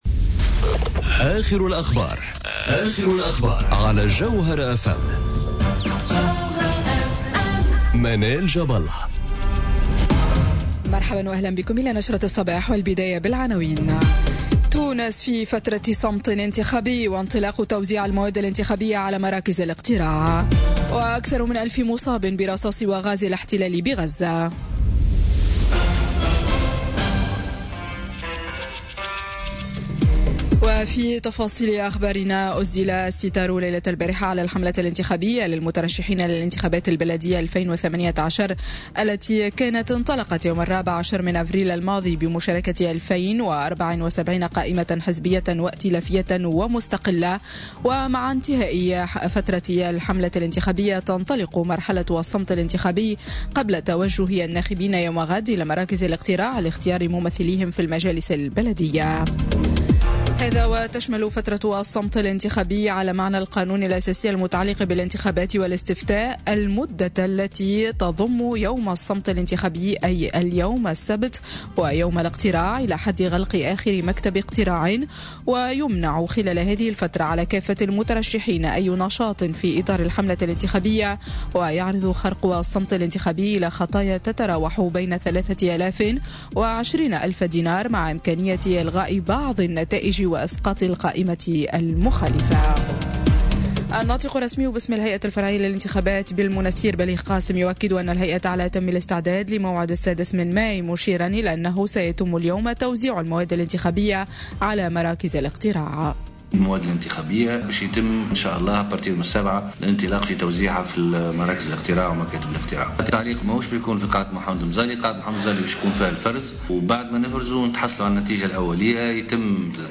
نشرة أخبار السابعة صباحا ليوم السبت 5 ماي 2018